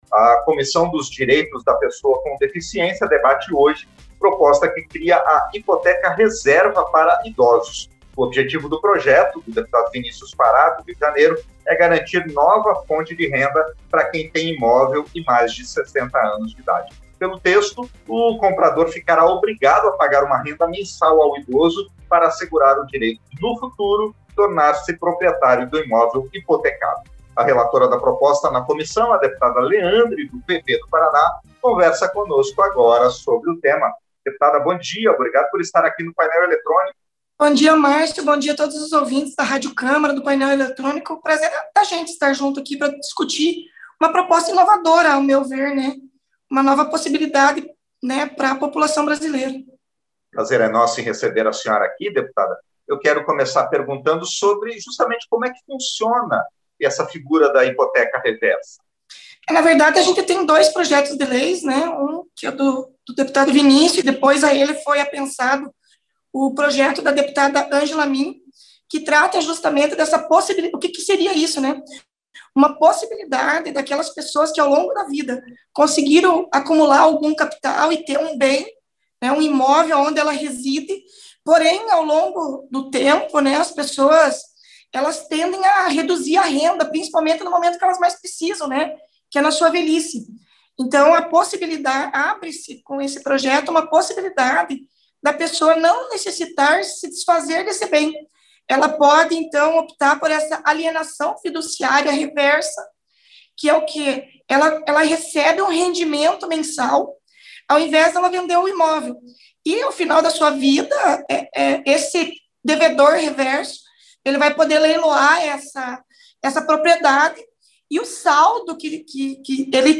Entrevista - Dep. Leandre (PV-PR)